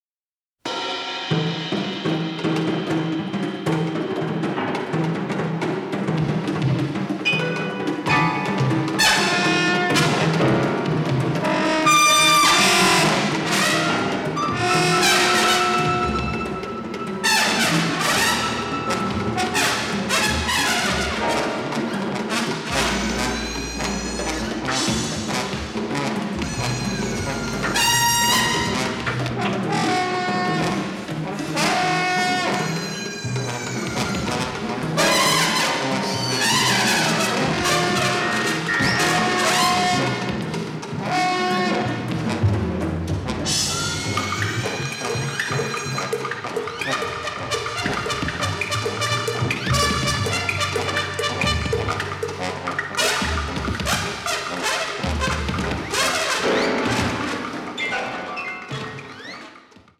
avantgarde score
It had less music, but it was all in stereo.